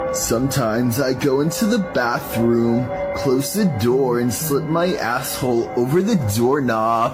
doorknob
doorknob.mp3